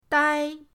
dai1.mp3